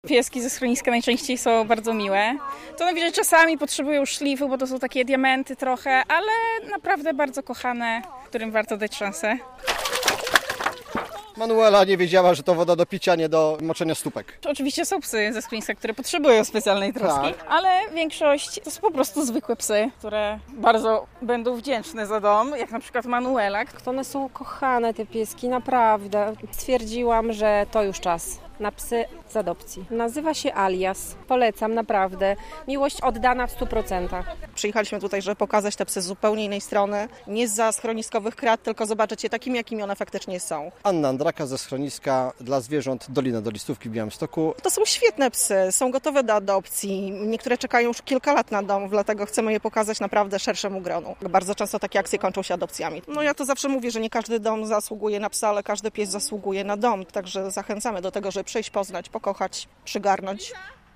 W ogrodach Pałacu Branickich można adoptować psa - relacja